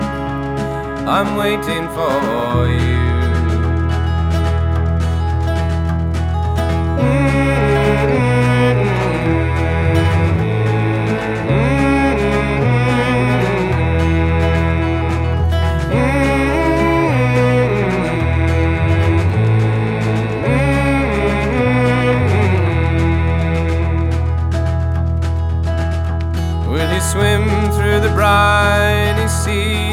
Жанр: Музыка из фильмов / Саундтреки
# TV Soundtrack